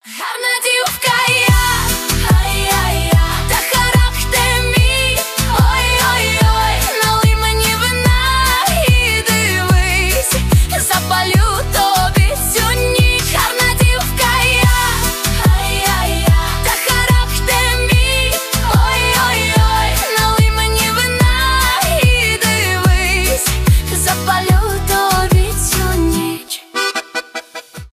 поп , нейросеть